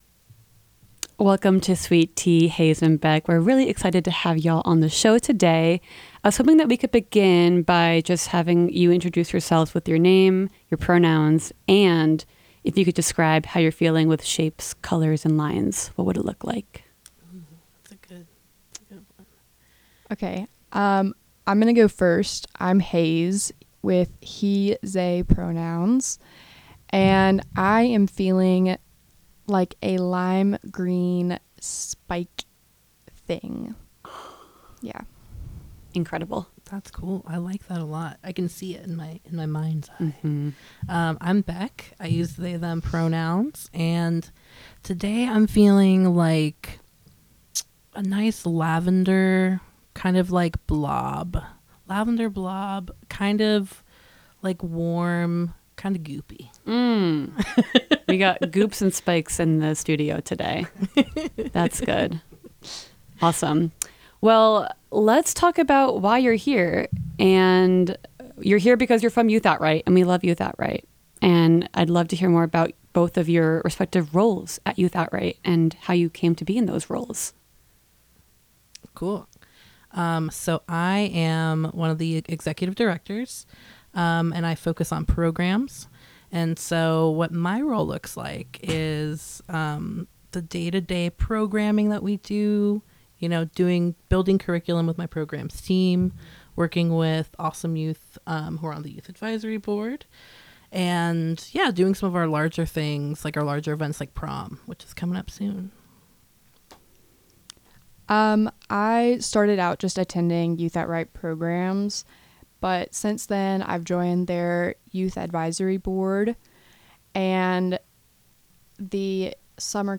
Sweet Tea is a talk/magazine format radio show on 103.3 Asheville FM that celebrates everything LGBTQIA+ in Asheville. We cover news, events, and topics of interest to the queer community.